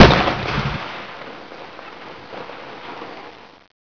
なのでテレビを消したら窓からボンボンという花火の音が聞こえてきた。